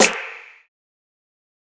TC SNARE 18.wav